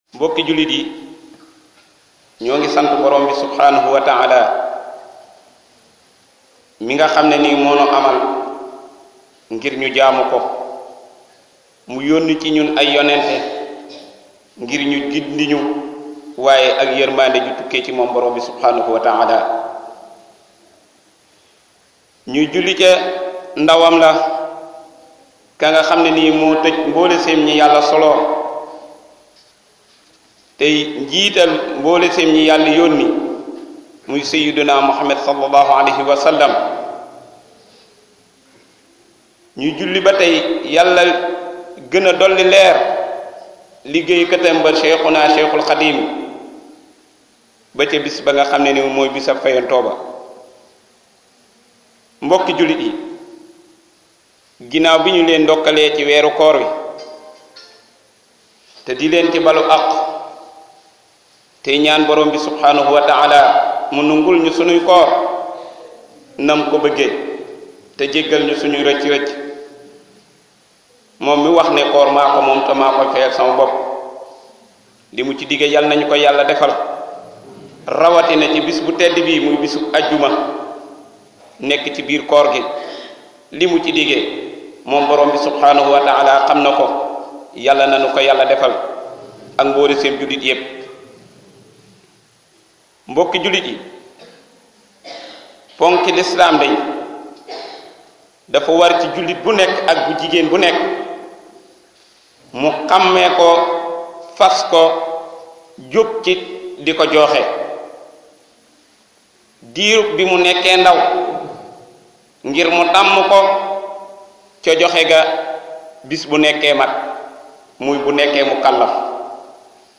Houtba-Adiouma-2-Juin-2017-Touba-Manaaroul-Houda.mp3